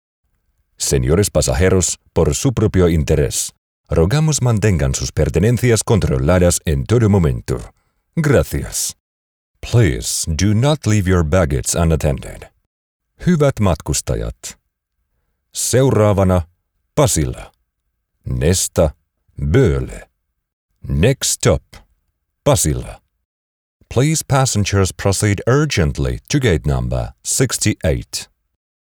Male
I have a deep, persuasive and memorable voice.
Foreign Language
Multilingual Announcements
0825Multilingual-Public-Announcements.mp3